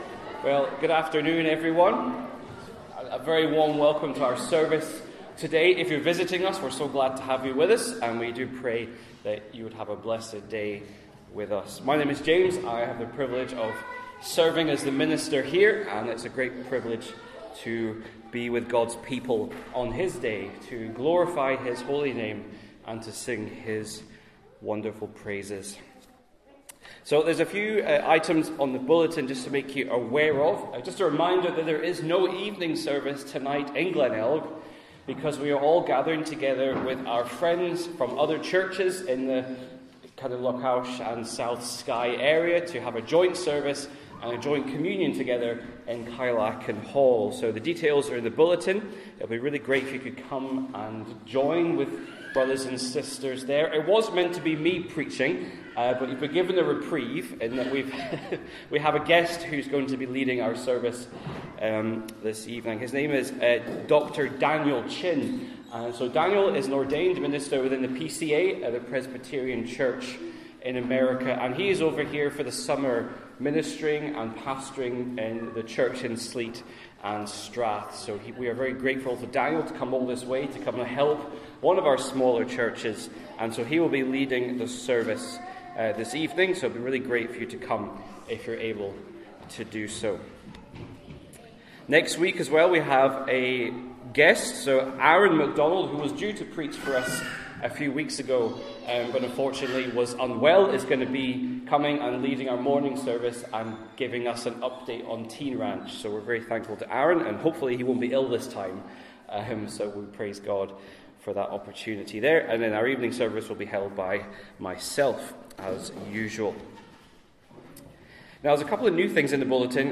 12-Noon-Service.mp3